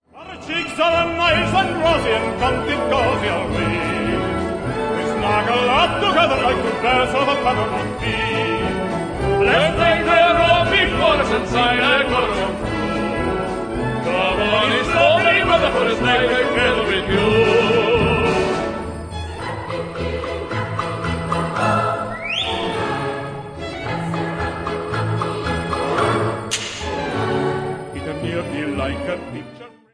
- Harp
Children Choir
key: D-major